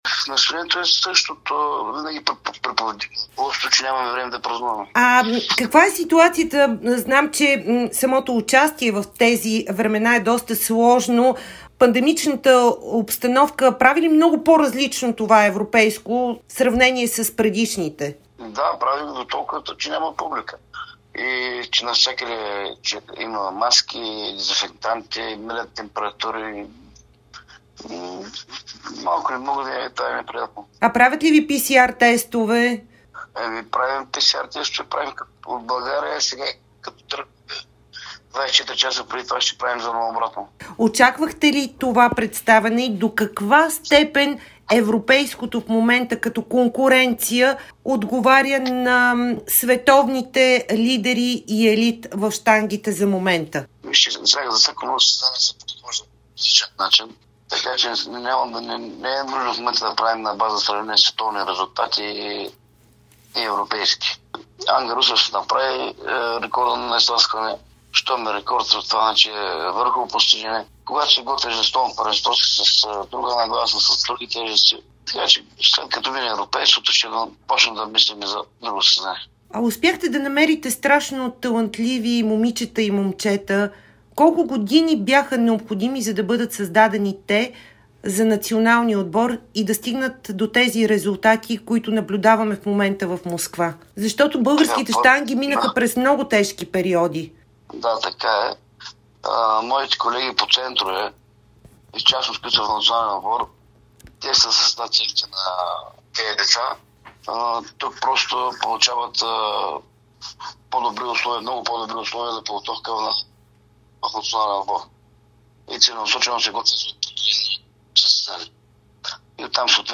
даде ексклузивно интервю за Дарик радио и dsport от Москва